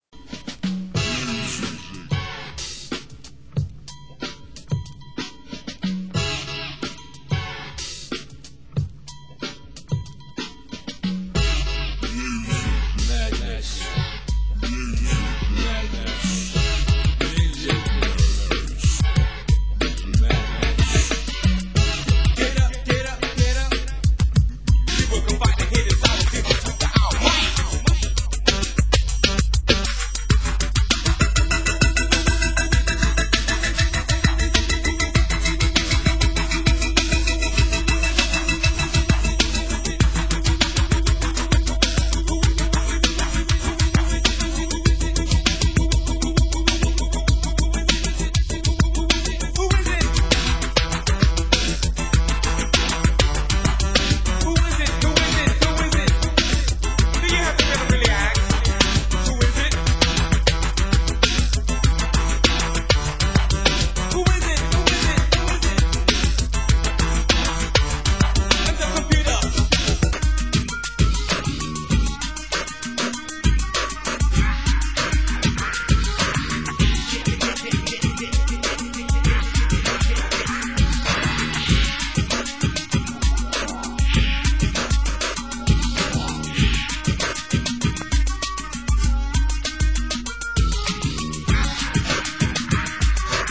Genre: Electro